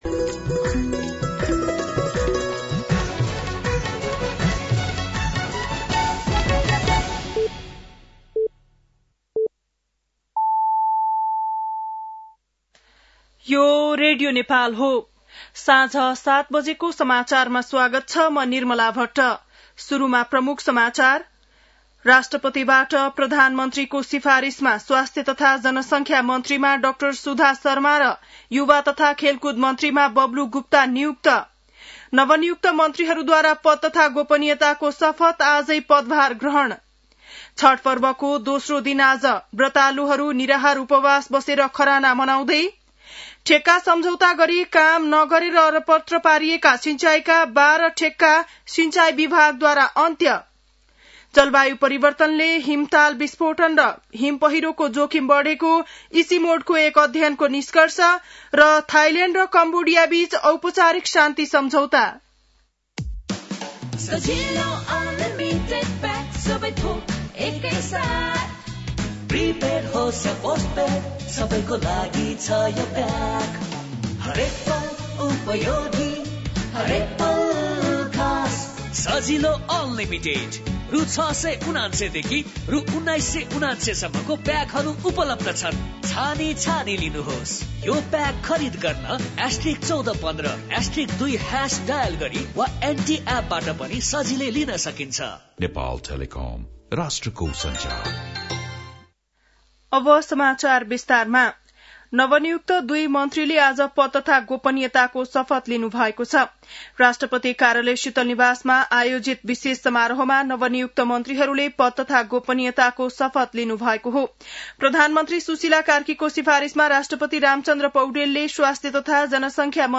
बेलुकी ७ बजेको नेपाली समाचार : ९ कार्तिक , २०८२
7.-pm-nepali-news-1-1.mp3